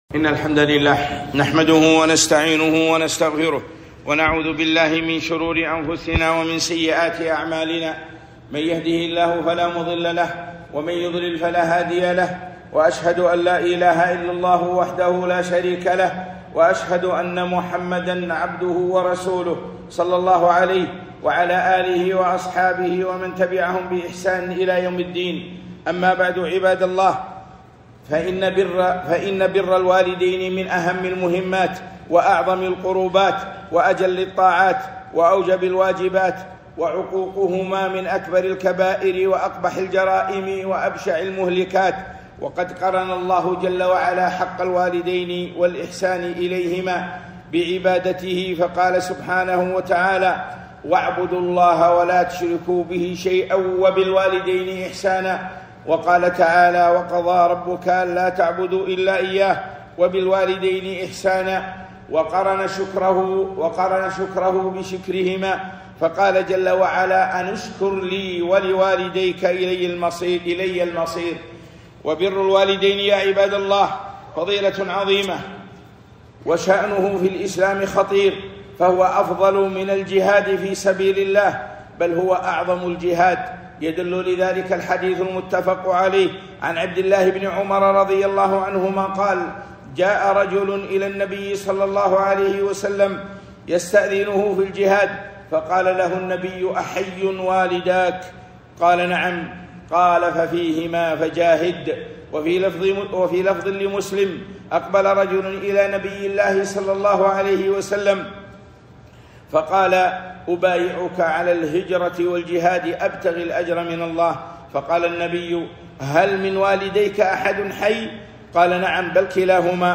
خطبة - بر الوالدين